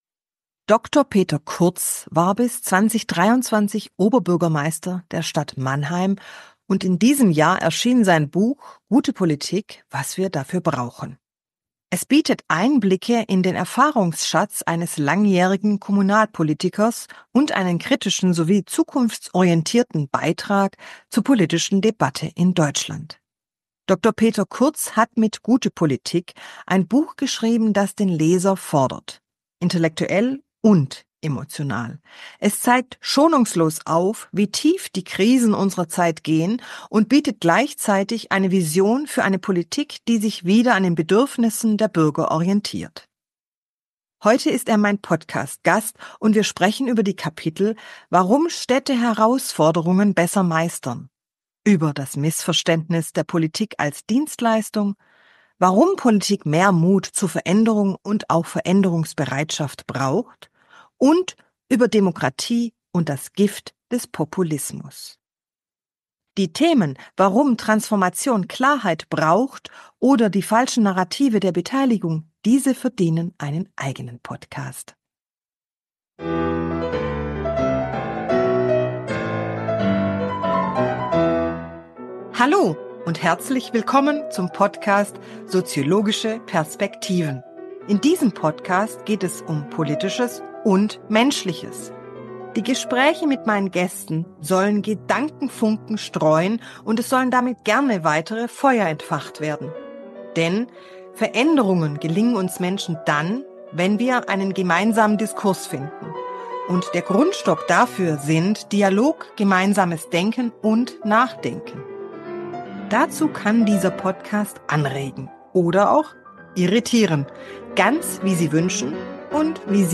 Heute ist Dr. Peter Kurz mein Podcast-Gast ... Wir sprachen u.a. über Städte und ihre Kreativität, Populismus, mutige Politik, und warum Politik keine Dienstleistung ist.